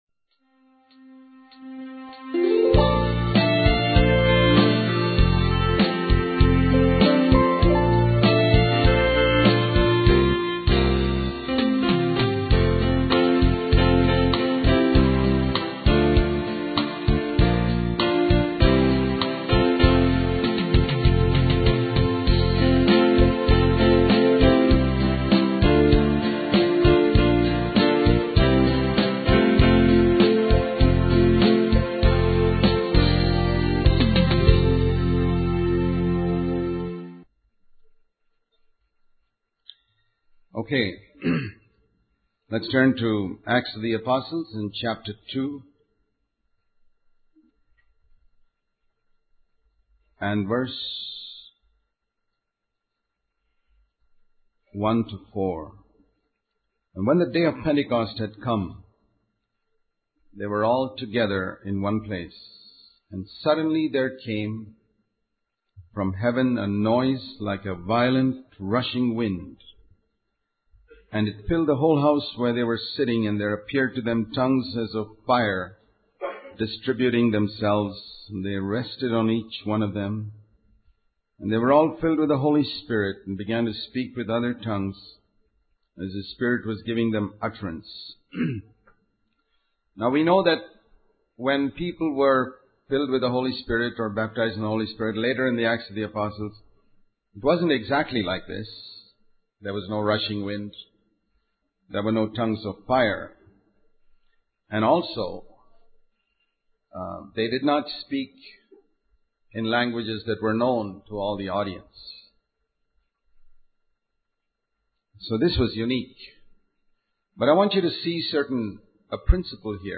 In this sermon, the speaker discusses the importance of not reading the Bible in isolated chapters but rather understanding the connections between different passages. He encourages those who have the habit of reading one chapter a day to start by reading the previous three verses of the last chapter they read.